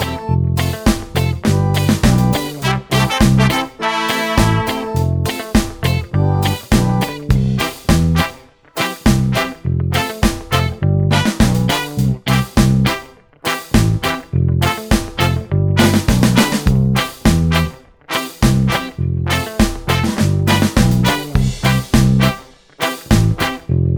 no Backing Vocals Ska 2:54 Buy £1.50